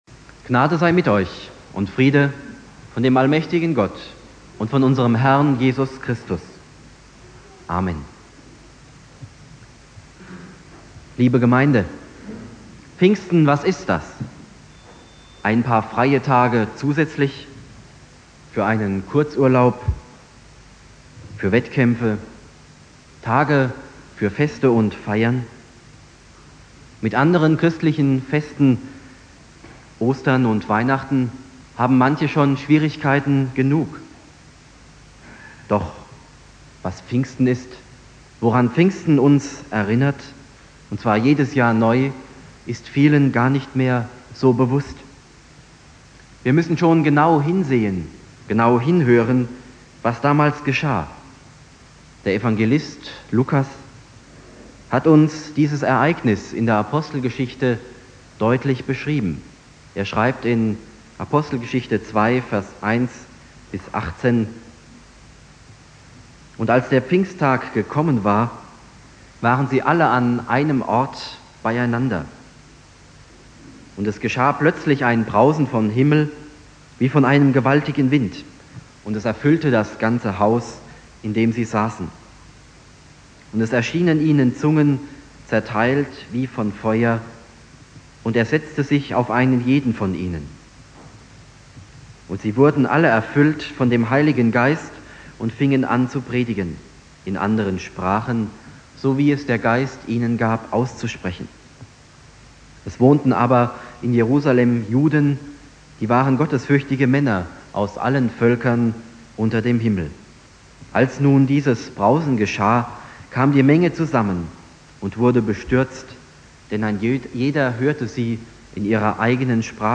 Pfingstsonntag Prediger